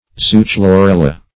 Search Result for " zoochlorella" : The Collaborative International Dictionary of English v.0.48: Zoochlorella \Zo`o*chlo*rel"la\, n. [NL., dim. from Gr. zw^,on an animal + ? green.]